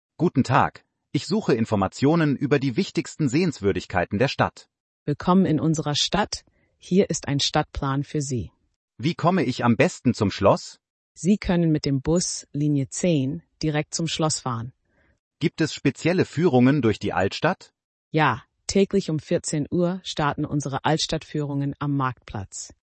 Dialog im Tourismusbüro Stimme - schulKI